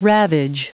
Prononciation du mot ravage en anglais (fichier audio)
Prononciation du mot : ravage